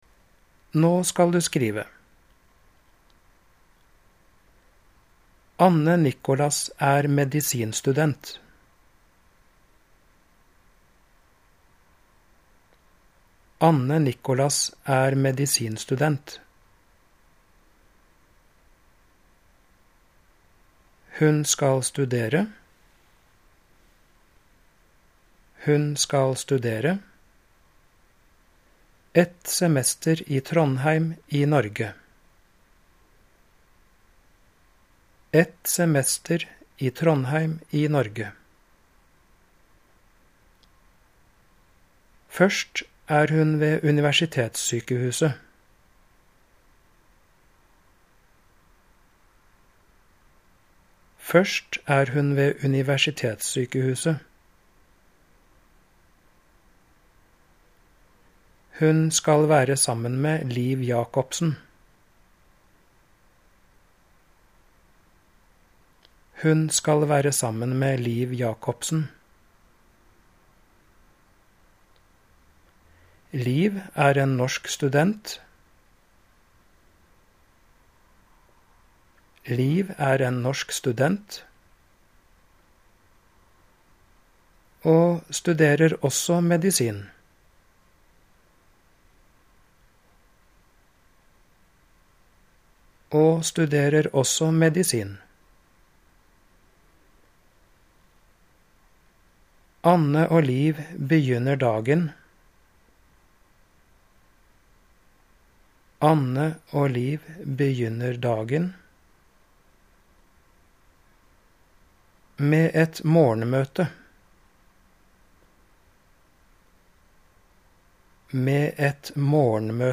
Diktat
Diktaten leses tre ganger: